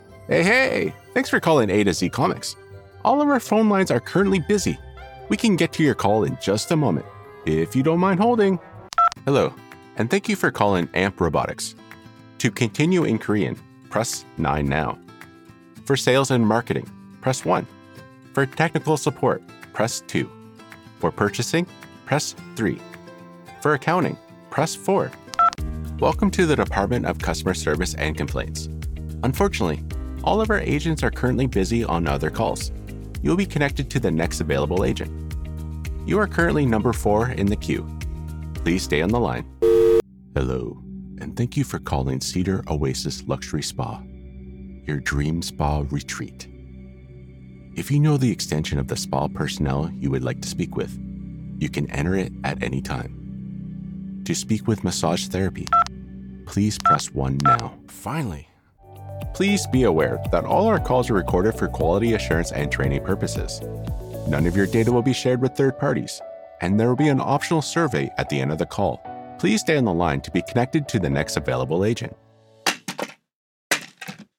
Deep authoritative male voice
Phone Greetings / On Hold
Humor Fun Relatable On Hold